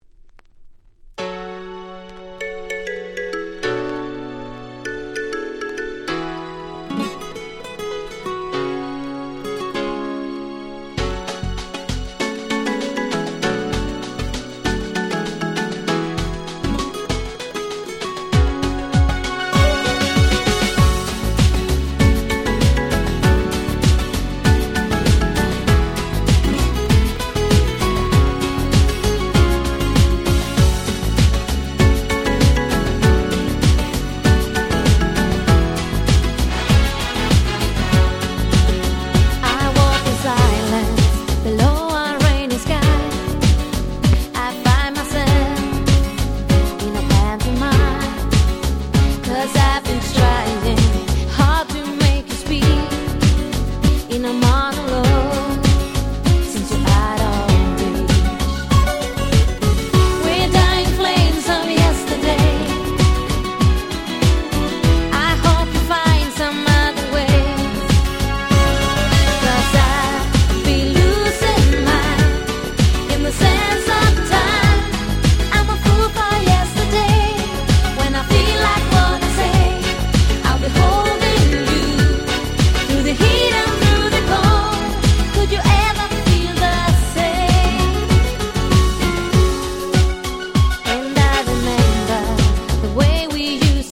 この辺のRagga Pop物、キャッチーでやっぱり最高ですよね。